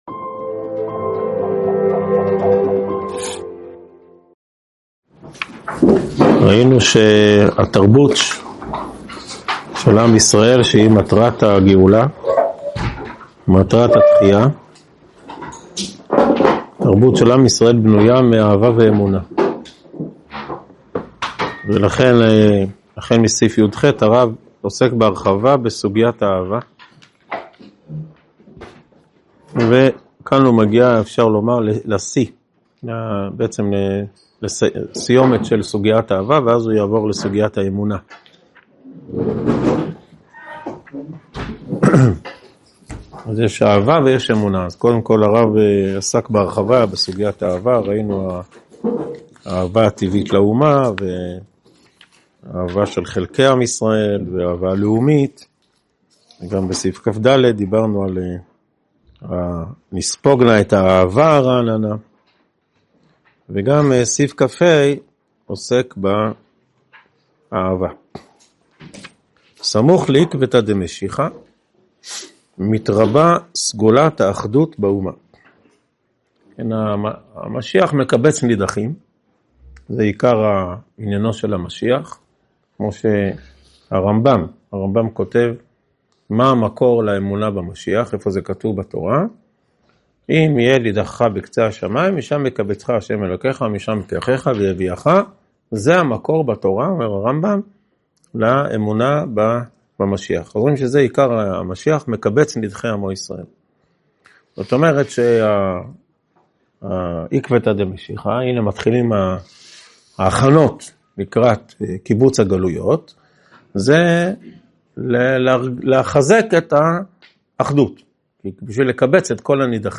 הועבר בישיבת אלון מורה בשנת תשפ"ד.